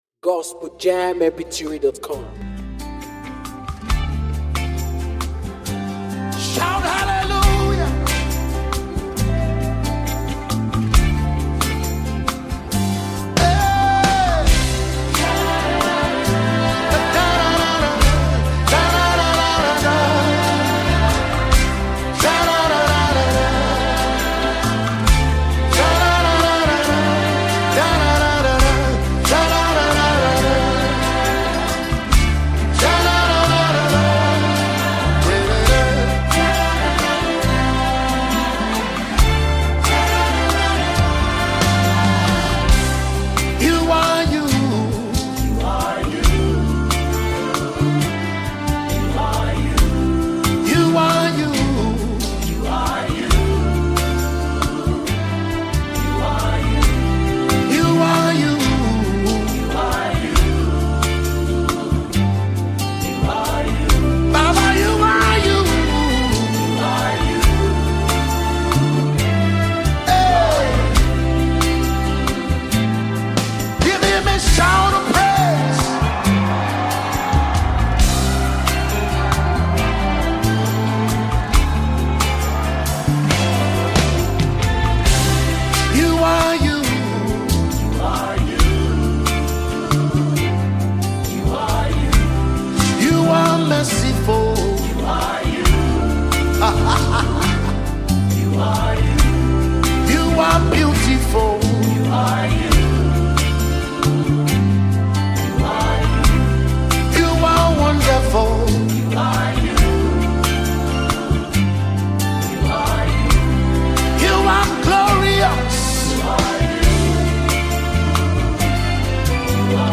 Popular Nigerian Gospel Singer